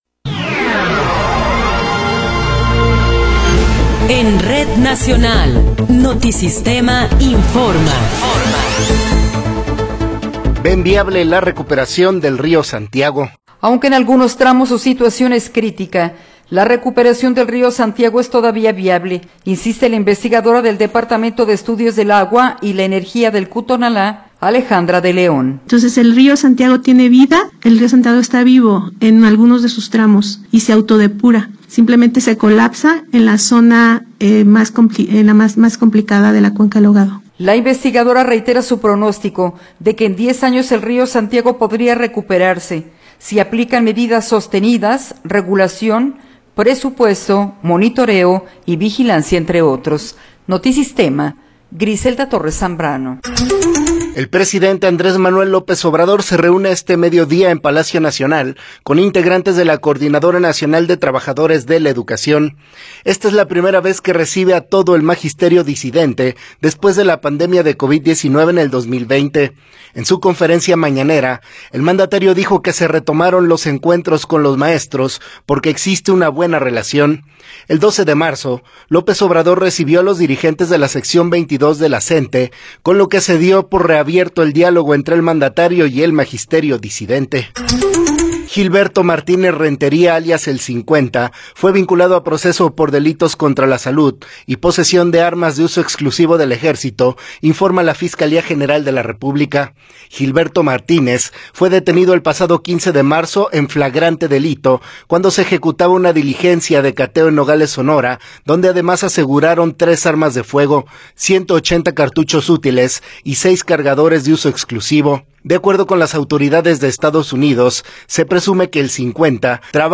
Noticiero 13 hrs. – 1 de Abril de 2024
Resumen informativo Notisistema, la mejor y más completa información cada hora en la hora.